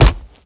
melee_hit_03.ogg